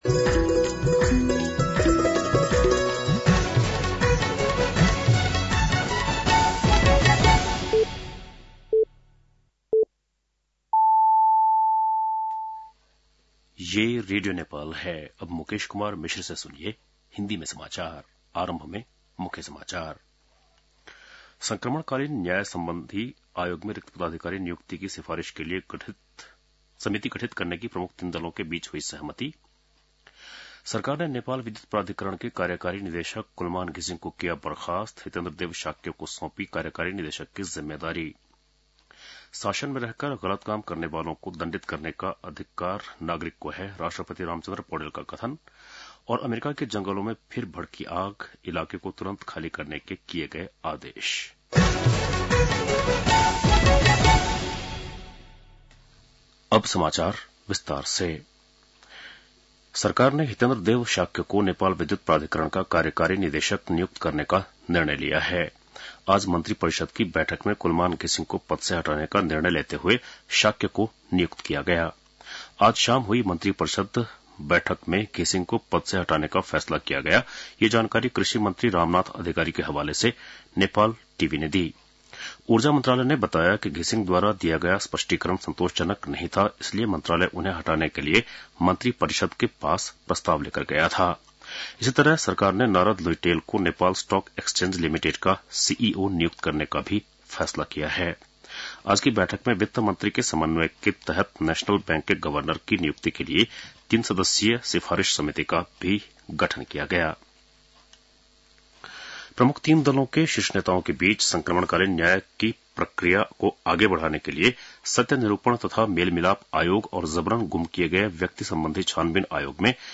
बेलुकी १० बजेको हिन्दी समाचार : ११ चैत , २०८१